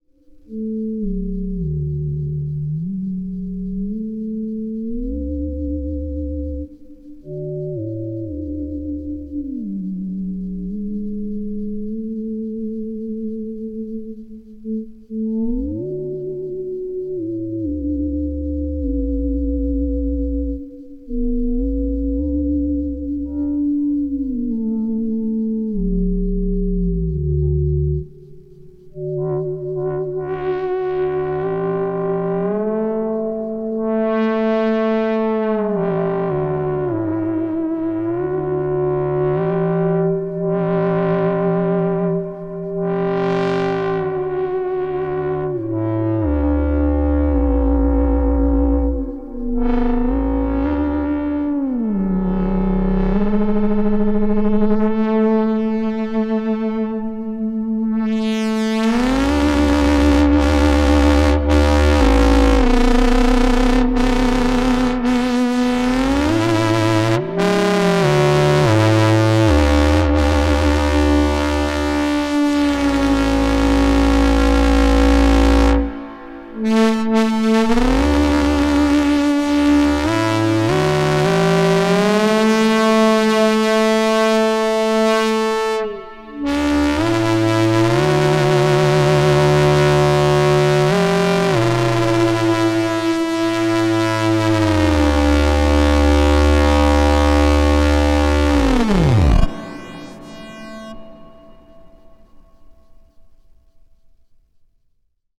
Ring modulation (i.e. 4 quadrant AM) can produce some crazy bass content!
Some D-Lev AM presets: